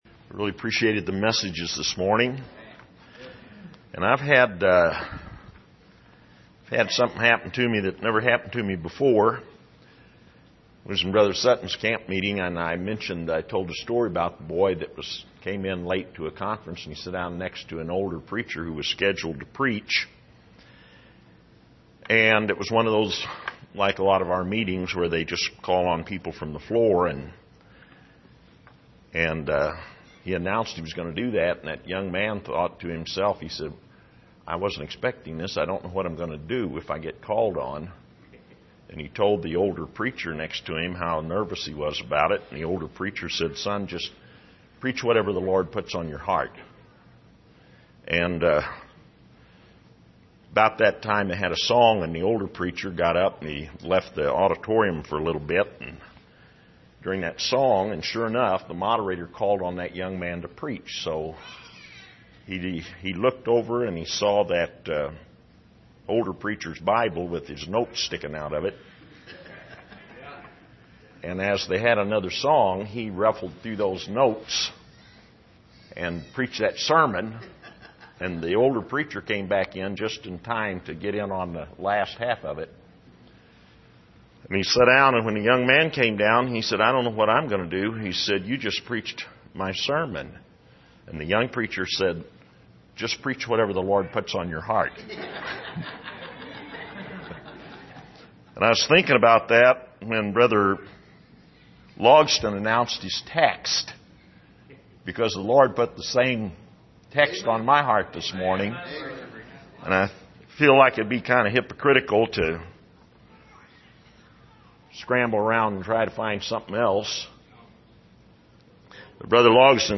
2017 Missions Conference Passage: Matthew 9:35-38 Service: Missions Conference Things We Need To Understand « Why Can’t We See What He Saw?